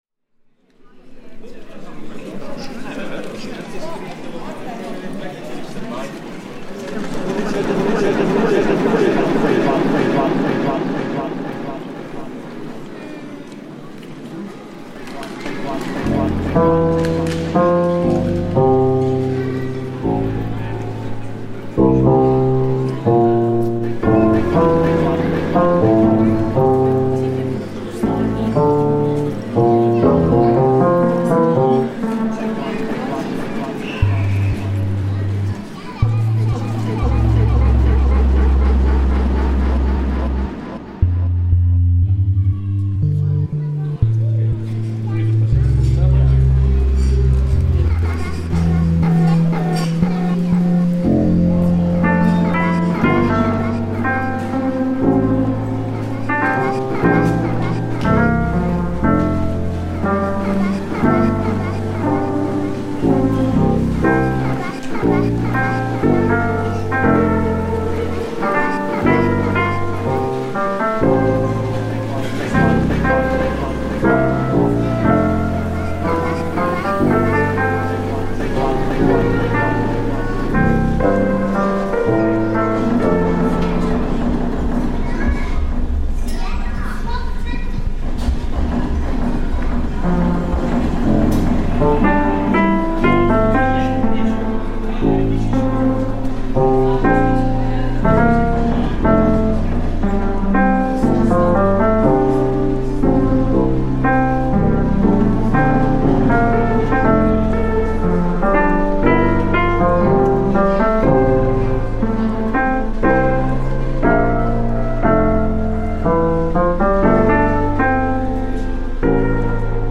Siena soundwalk reimagined